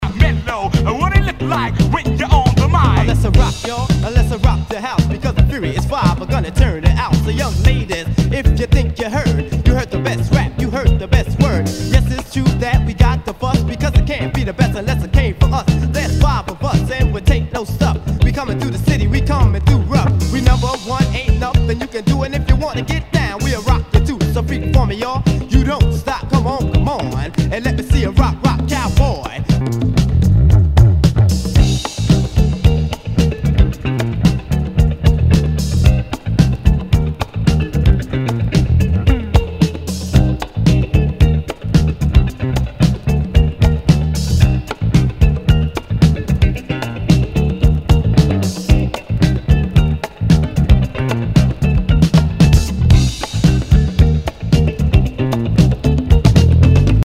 HIPHOP/R&B
[VG ] 平均的中古盤。スレ、キズ少々あり（ストレスに感じない程度のノイズが入ることも有り）